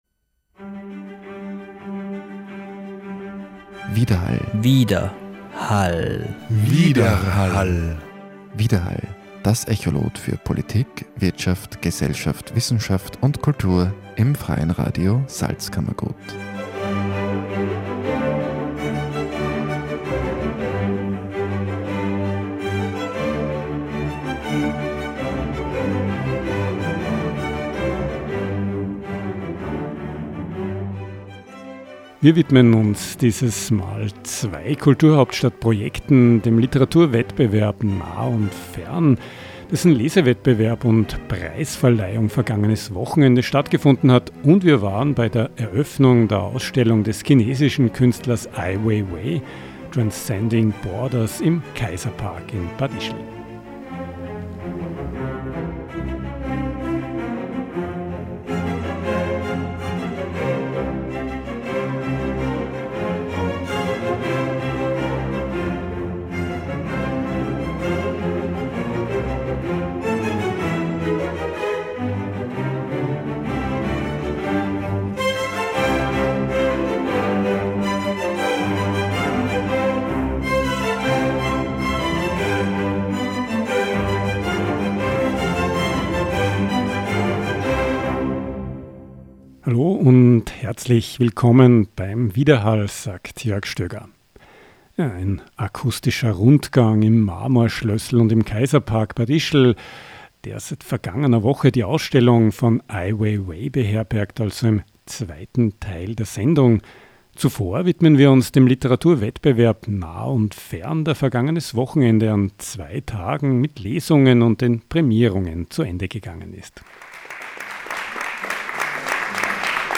Am 22. und 23. Juni fanden in der Trinkhalle in Bad Ischl der abschließende Lesebewerb sowie die Prämierungen des Literaturwettbewerbs „nah und fern“ statt – ein Projekt der europäischen Kulturhauptstadt Salzkammergut 2024.